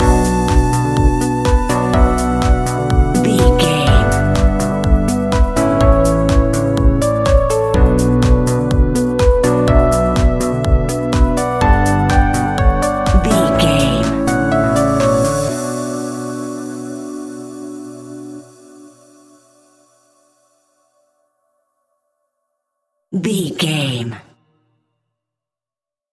Fast paced
Aeolian/Minor
groovy
uplifting
driving
energetic
drum machine
synthesiser
house
electro house
funky house
synth bass